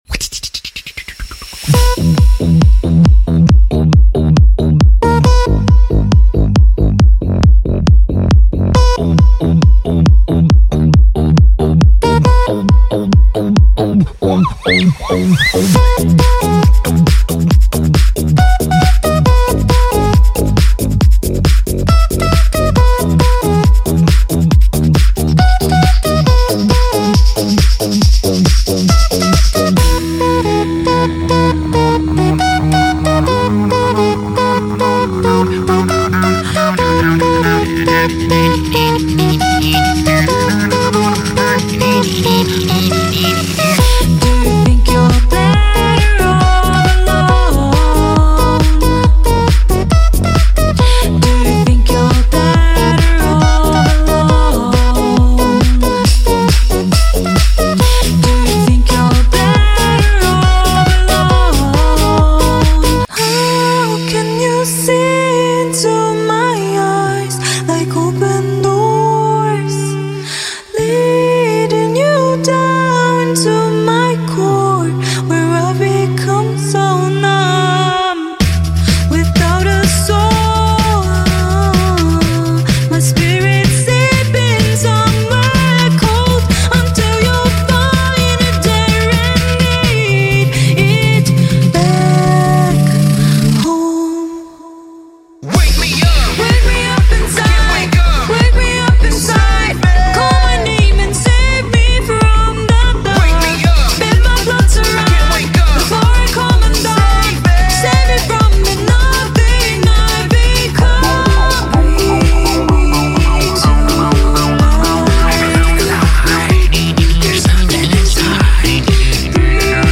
Choose your favorite beatbox song sound effects free download